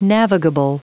Prononciation du mot navigable en anglais (fichier audio)
Prononciation du mot : navigable